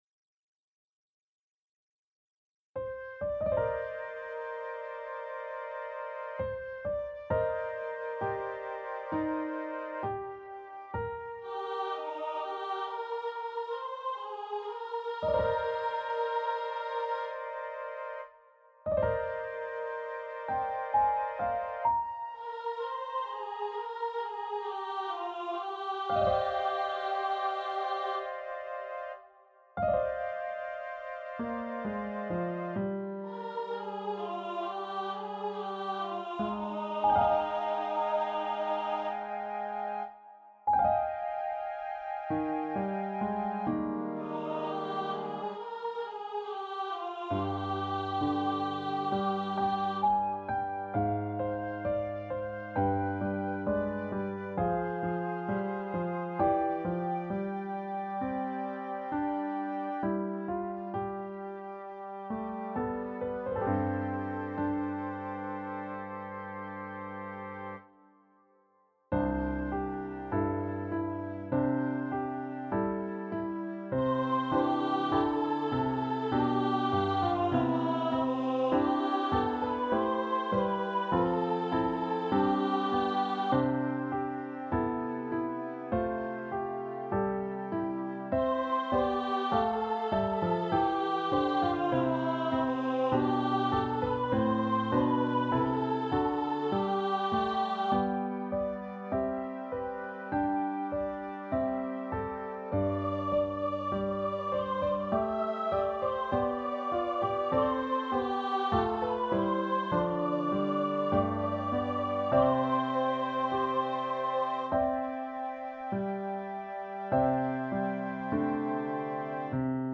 • Music Type: Choral
• Voicing: 2-Part Treble
• Accompaniment: Keyboard
A beautiful work for two-part trebles and organ
with an imaginative organ accompaniment